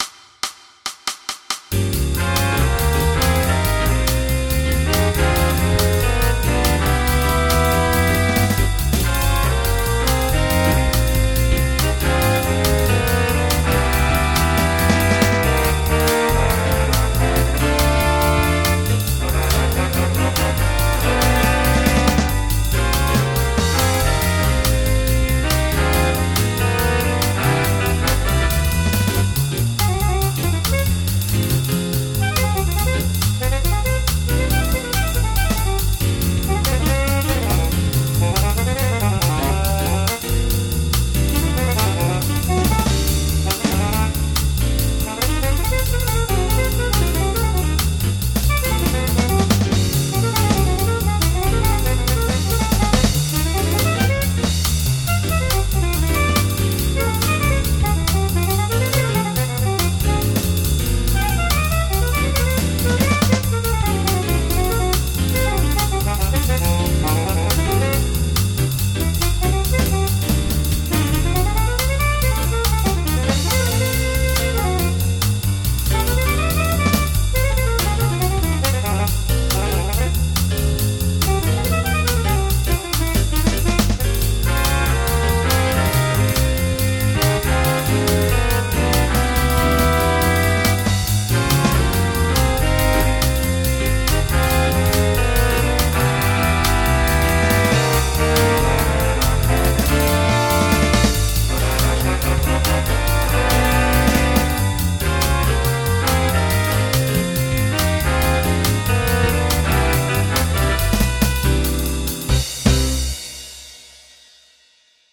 comme n'importe quel programme midi, utiliser un expandeur ou un lecteur de soundfonts software (dxi dans le cas de Band In A Box) avec une bonne soundfont GM améliore grandement les sons
exemple avec le vtsi sfz et la soundfont Fluid GM (gratuits)